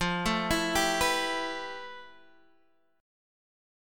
FM#11 chord